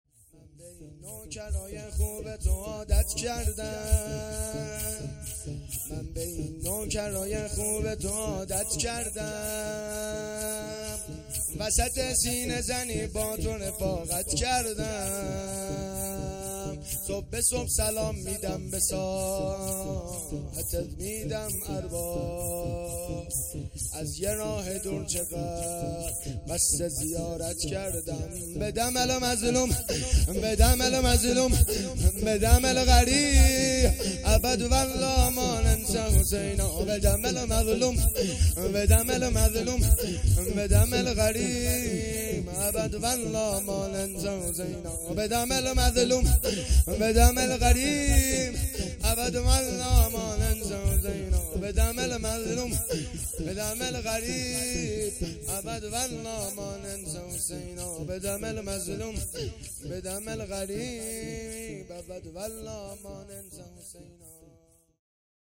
شورپایانی
شب پنجم محرم الحرام ۱۴۴۳